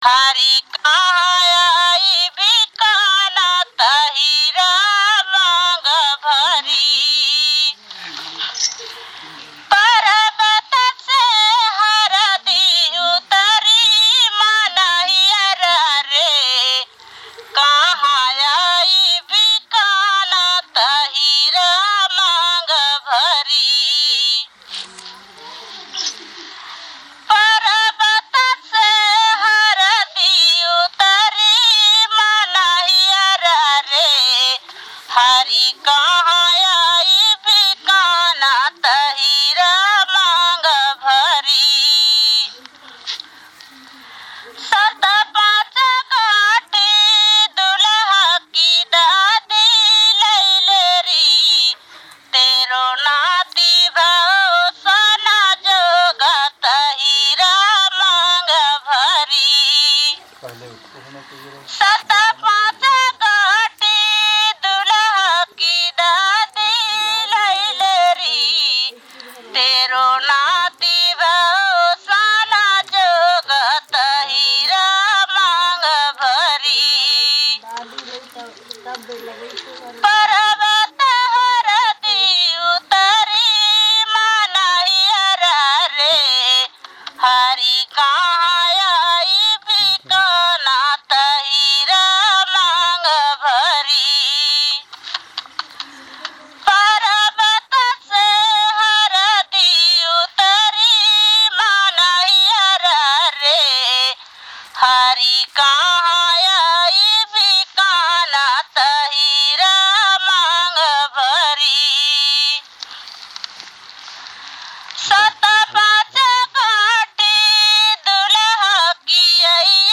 Performance of a holy song